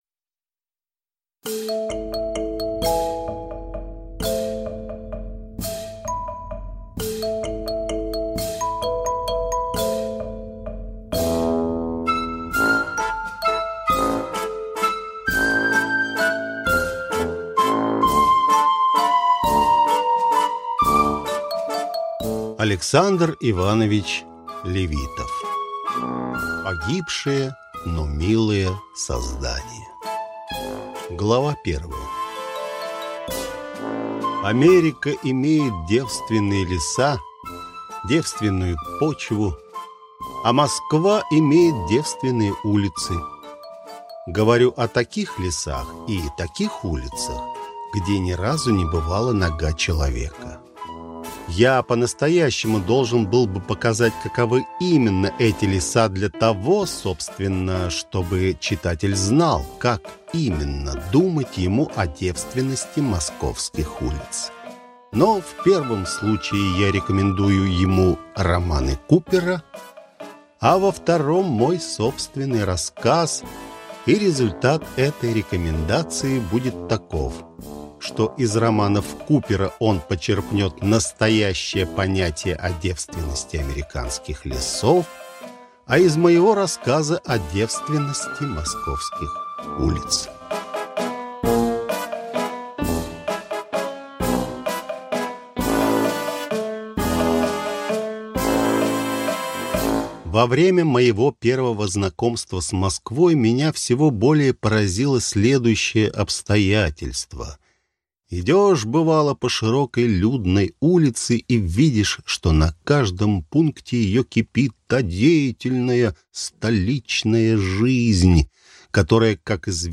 Аудиокнига Погибшее, но милое создание | Библиотека аудиокниг